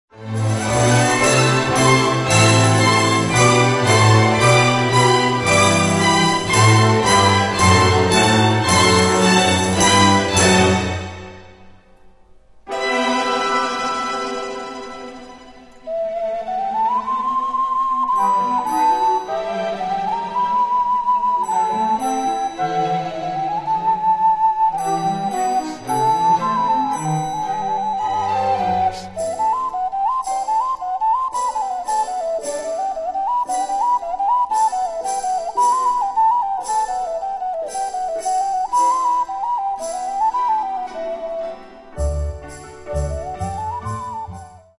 окарина, сопілка прима